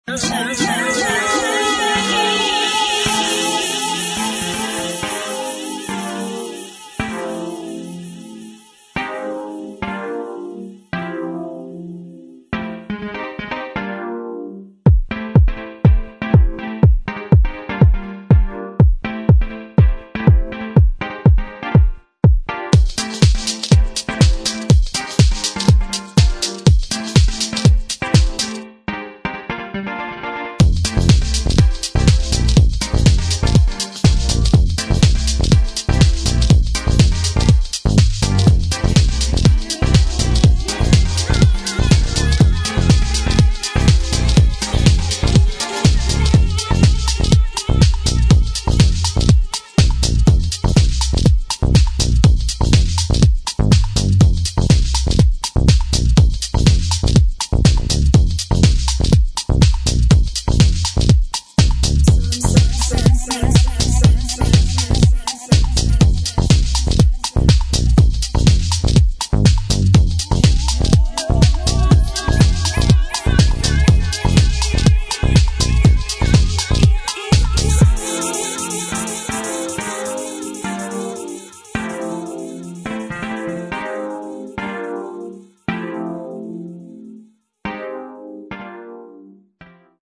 [ DEEP HOUSE / NY HOUSE ]